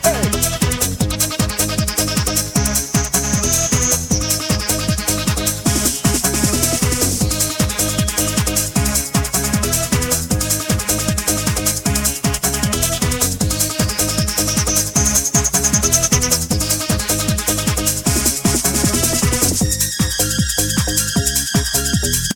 piosenka znaleziona na plycie cd - Muzyka elektroniczna